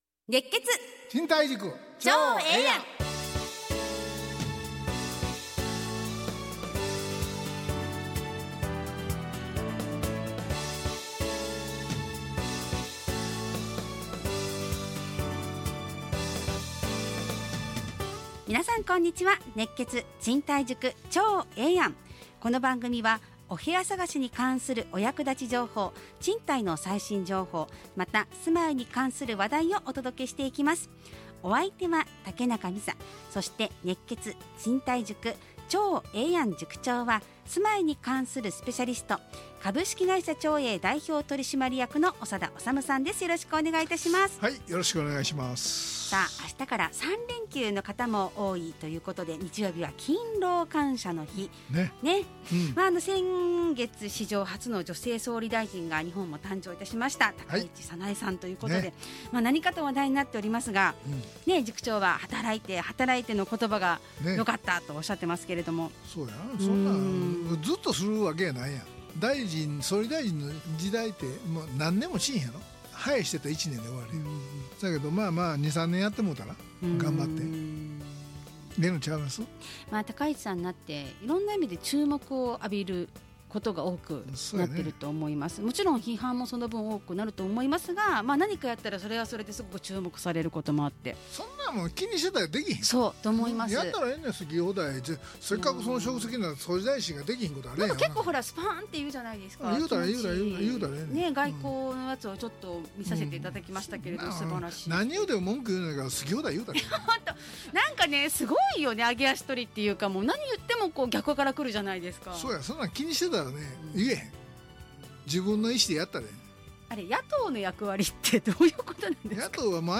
ラジオ放送 2025-11-21 熱血！